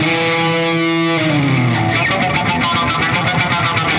Index of /musicradar/80s-heat-samples/120bpm